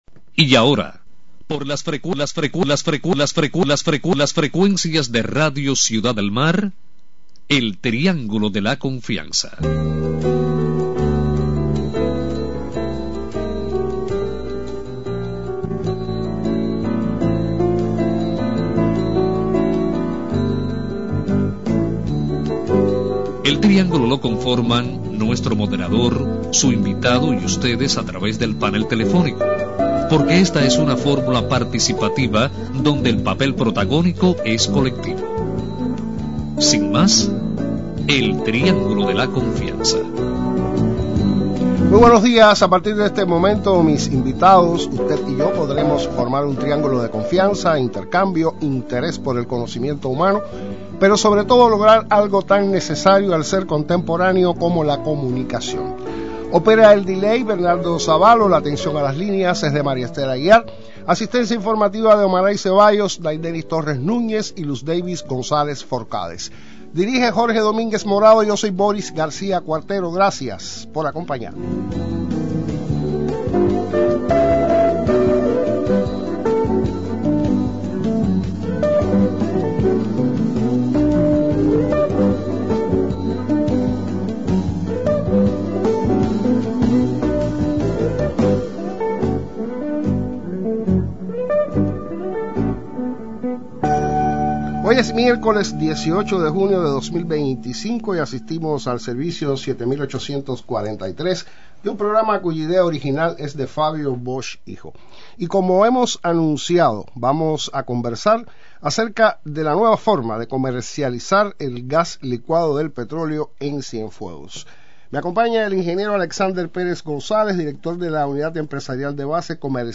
intercambian con los oyentes del Triángulo de la confianza, acerca del empleo de la opción Mi turno, de Transfermóvil, para la compra del gas licuado de petróleo.